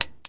A3XX: Update Overhead sounds
button2.wav